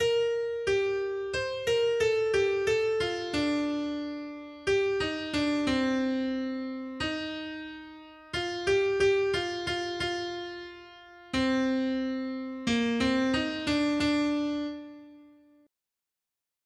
Noty Štítky, zpěvníky ol693.pdf responsoriální žalm Žaltář (Olejník) 693 Skrýt akordy R: Voď mě po cestě svých předpisů, Hospodine. 1.